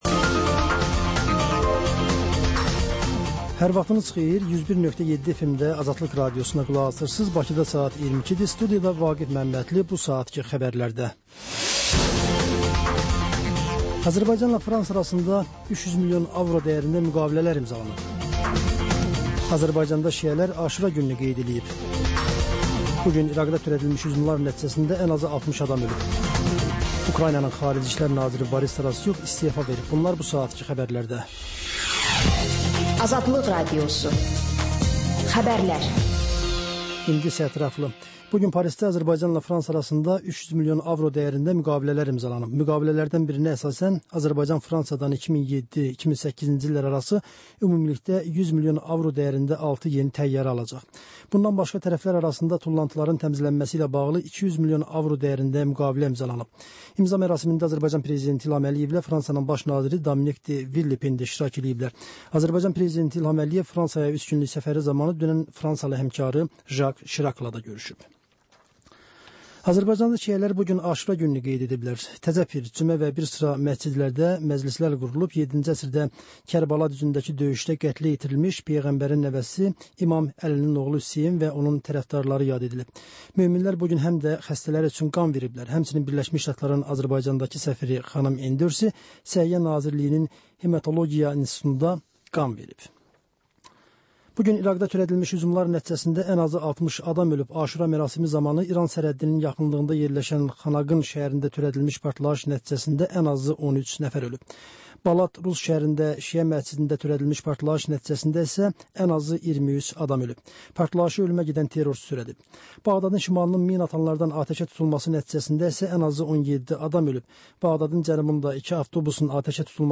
Xəbərlər, reportajlar, müsahibələr. Və: Şəffaflıq: Korrupsiya barədə xüsusi veriliş.